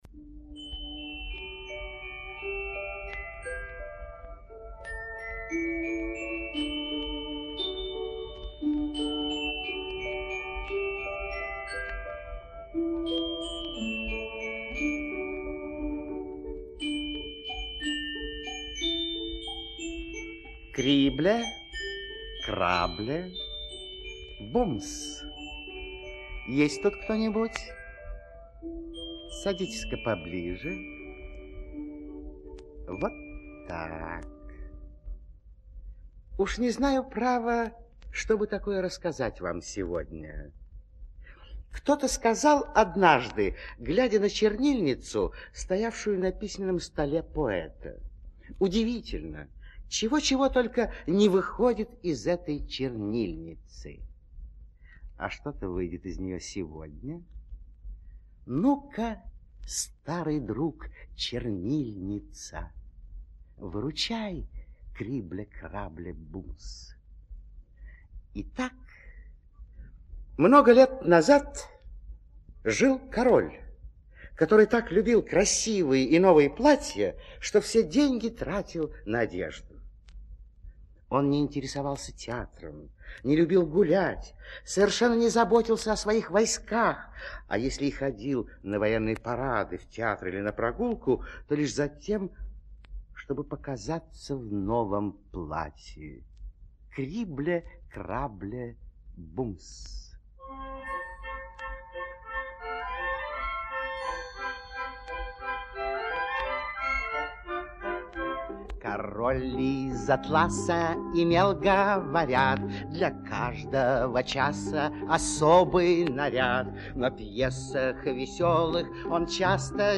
Звуковая книга в формате MP3 слушать СКАЧАТЬ формат mp3